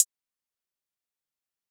Closed Hats
Hat 4.wav